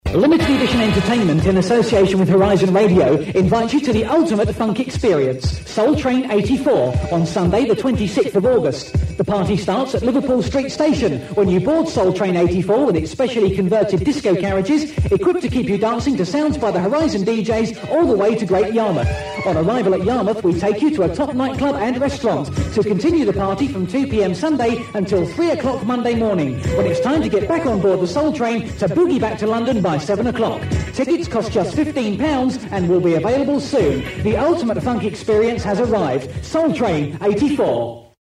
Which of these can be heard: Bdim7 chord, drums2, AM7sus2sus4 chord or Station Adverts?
Station Adverts